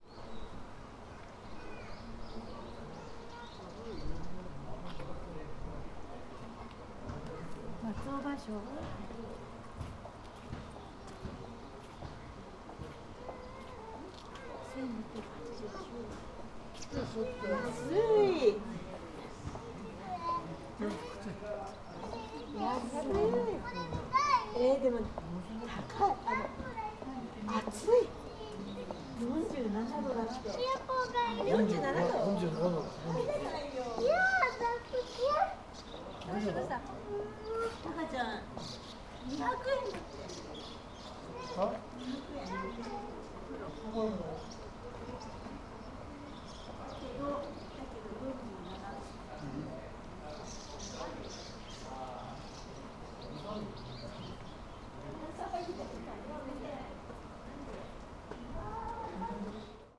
Fukushima Soundscape: Iizaka hot spring